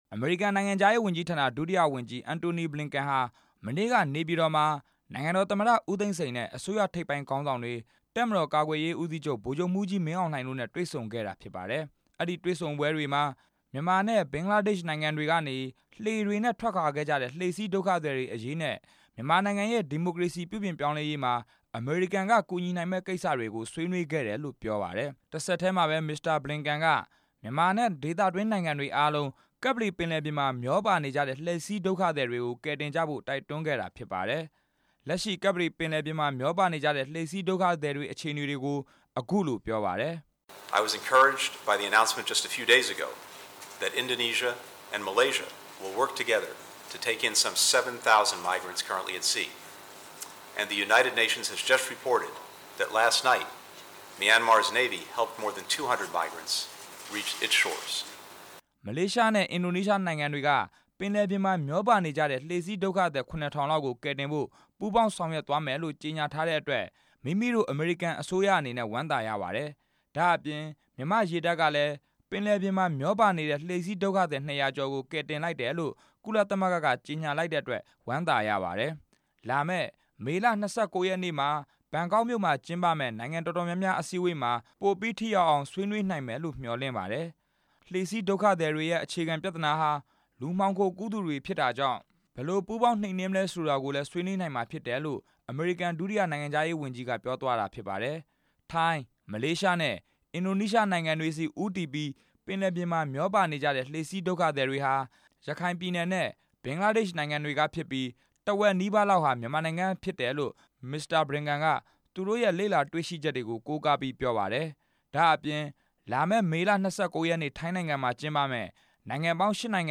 ရန်ကုန်မြို့ အမေရိကန်စင်တာ (American Center) မှာ ဒီနေ့ ညနေပိုင်းက ကျင်းပတဲ့ သတင်းစာ ရှင်းလင်းပွဲမှာ ဒုတိယနိုင်ငံခြားရေးဝန်ကြီး Mr. Blinken က အခုလို တိုက်တွန်း ပြောကြားလိုက်တာ ဖြစ်ပါတယ်။